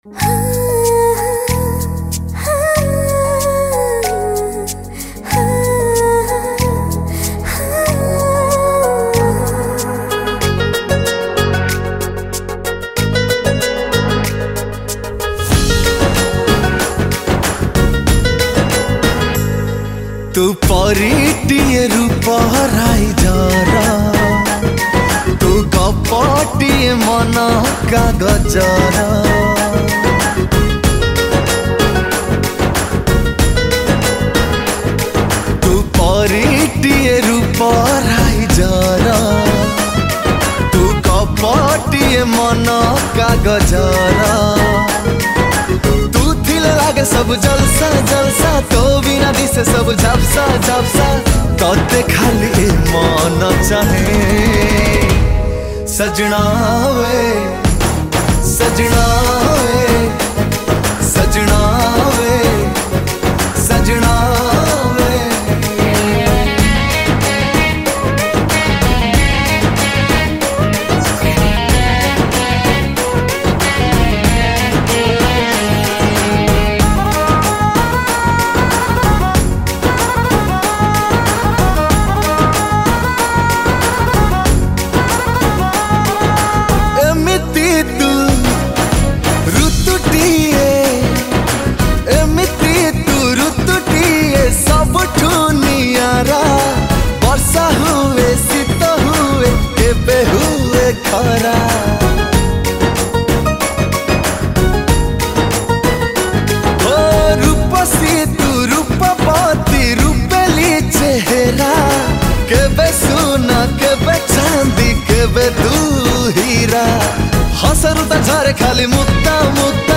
Romantic Odia Song